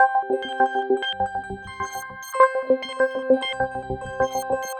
tx_synth_100_glitch_CG2.wav